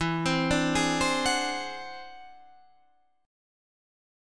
Listen to E+9 strummed